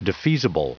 Prononciation du mot defeasible en anglais (fichier audio)
Prononciation du mot : defeasible